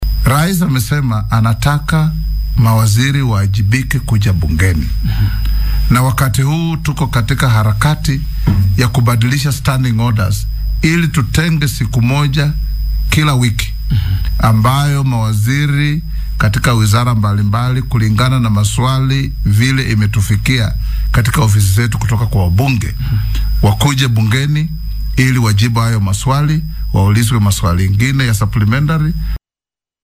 Guddoomiyaha baarlamaanka dalka Moses Wetang’ula oo wareysi gaar ah siiyay warbaahinta dowladda ee KBC ayaa wacad ku maray inuu ilaalin doono madax bannaanida baarlamaanka.